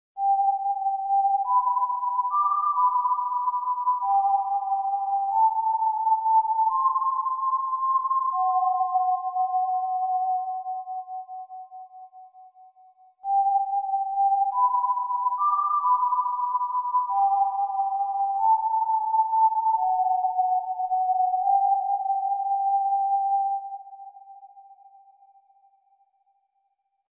Death Whistleee - Botão de Efeito Sonoro